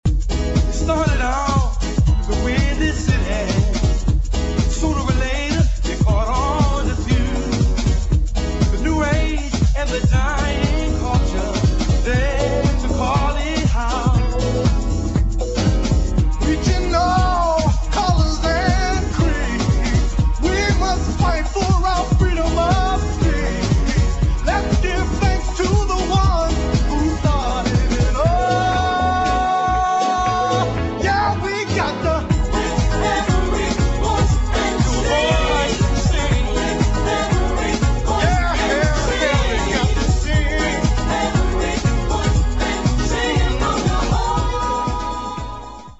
[ HOUSE / DISCO ]